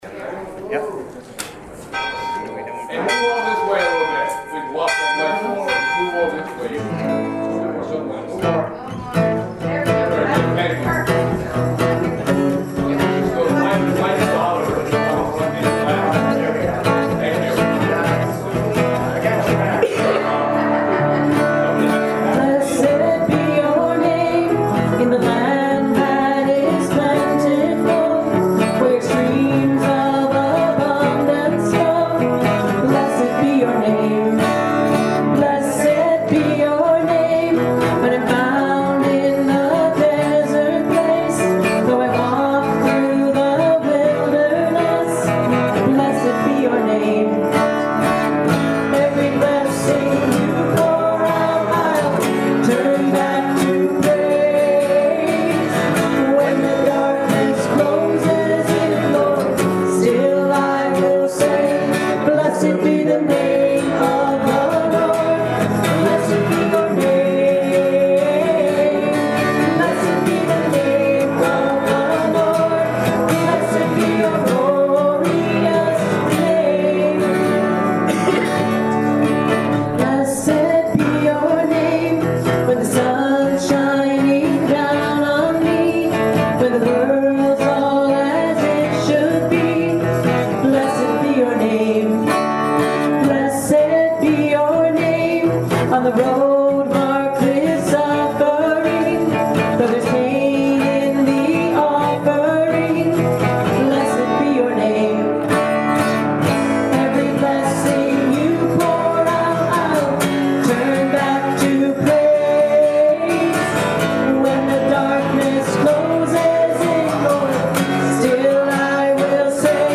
June 11th, 2017 Service Podcast
Hymn: #21 How Great Thou Art
Anthem: We Want To See Jesus Lifted High
Benediction & Choral Amen